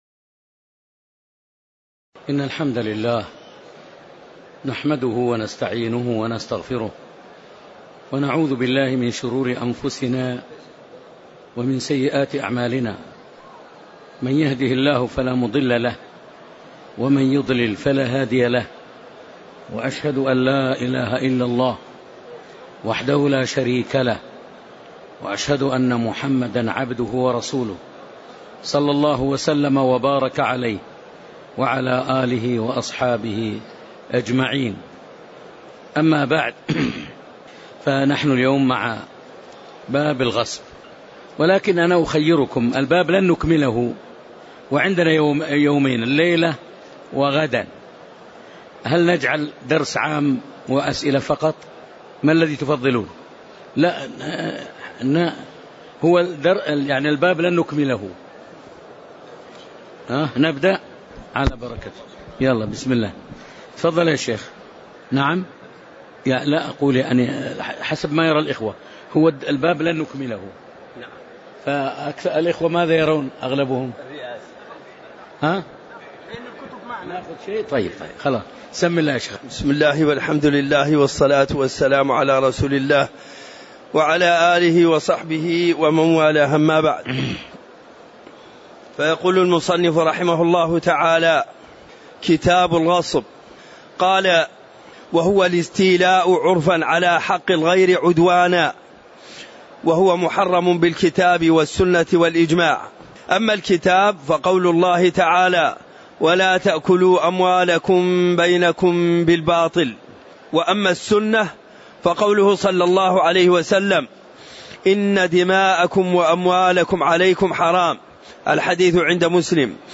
تاريخ النشر ١٩ رمضان ١٤٤٣ هـ المكان: المسجد النبوي الشيخ